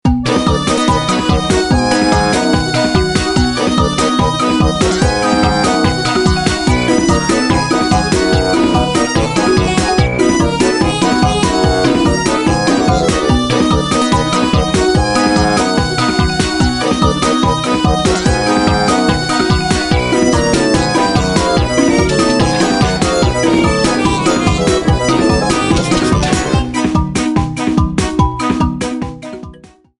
minigame music rearranged